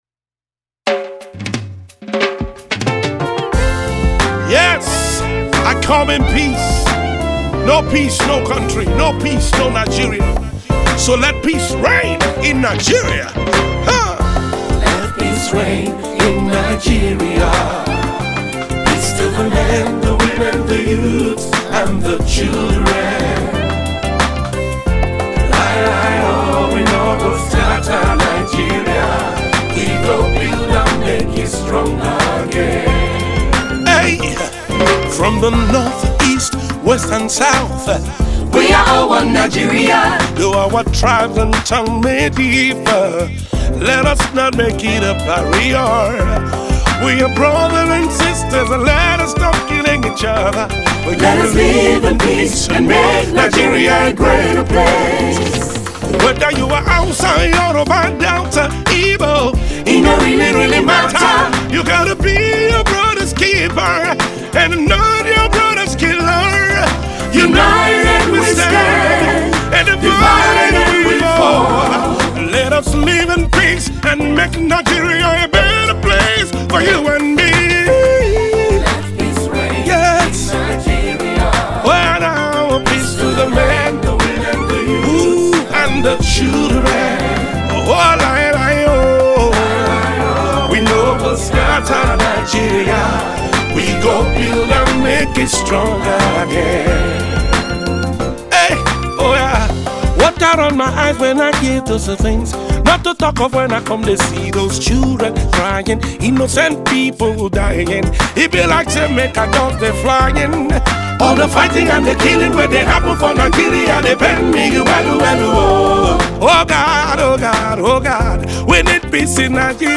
The accomplished Gospel Music Icon